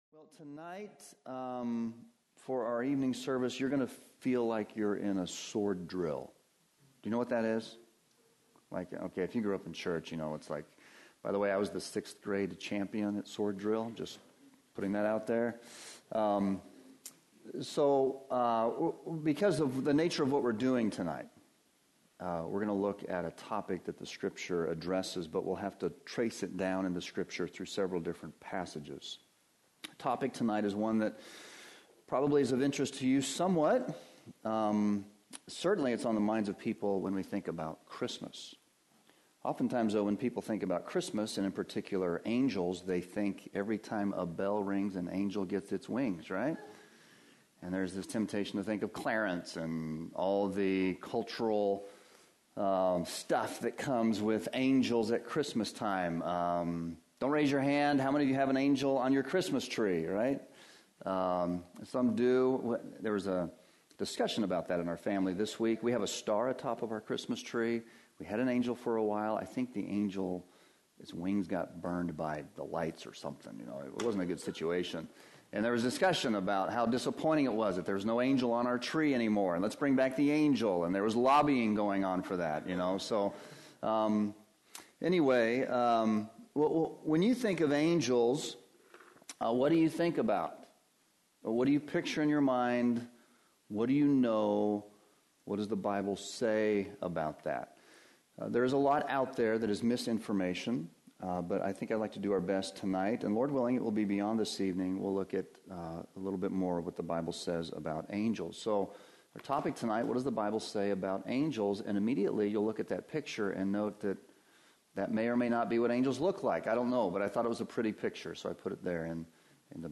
We trust you will be encouraged by the preaching and teaching ministry of Heritage Baptist Church in Windham, NH.